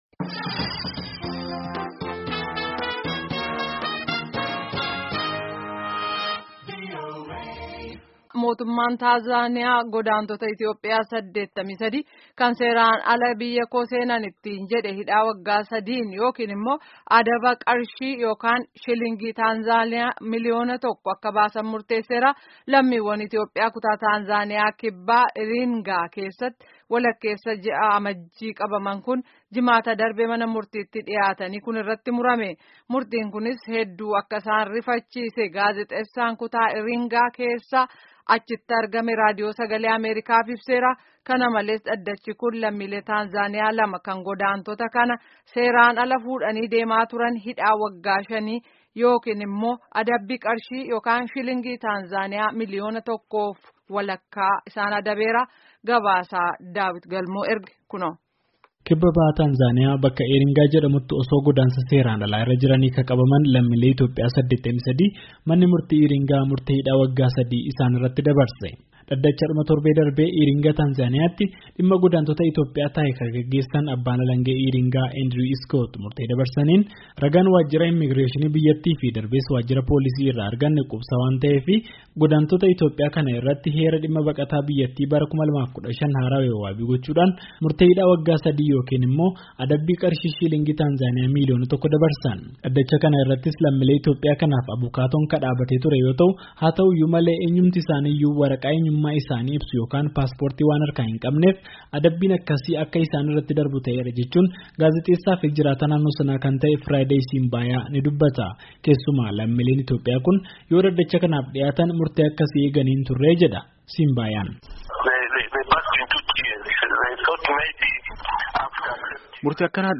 Gabaasni sagalee kunooti